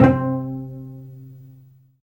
Index of /90_sSampleCDs/Roland - String Master Series/STR_Vcs Marc-Piz/STR_Vcs Pz.4 Oct
STR CLLO P05.wav